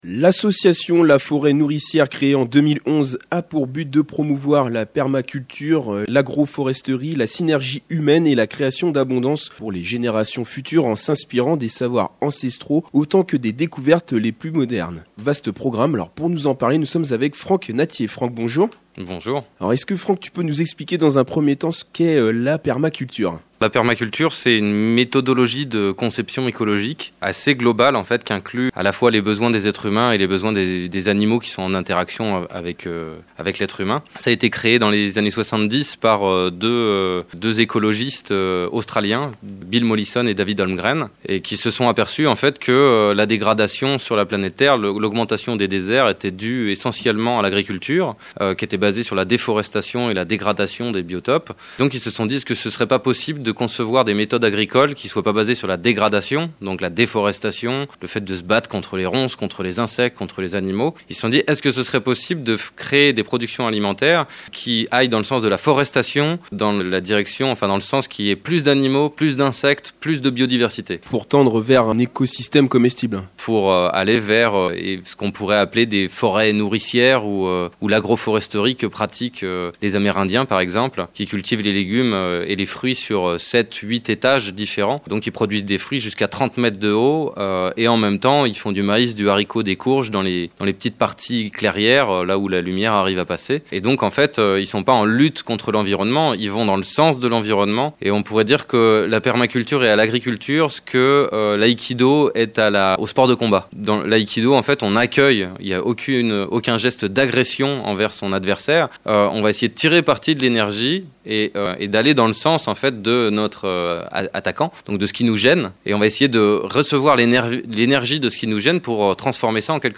interview faite par RADIO LASER (FM 95.9)